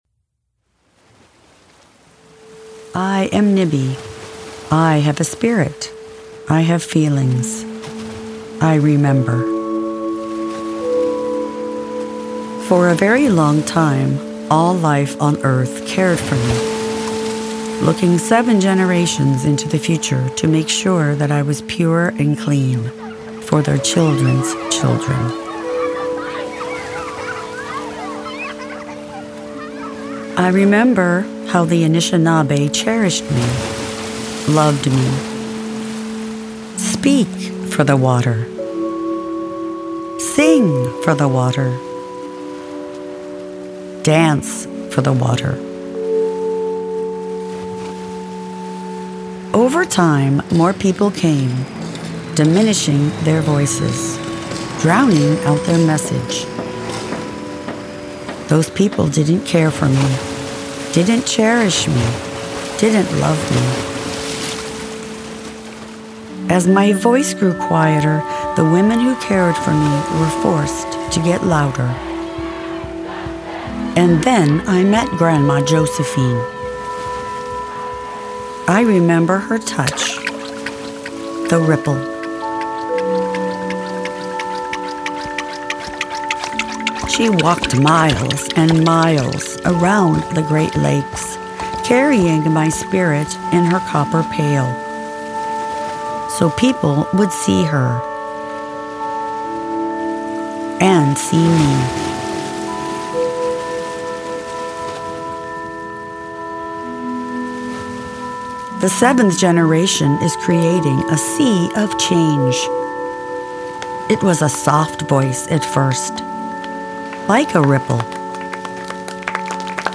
Listen to the first ten minutes of the audiobook, narrated by the author herself.